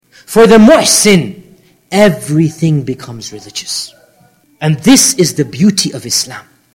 audio_level_too_high.mp3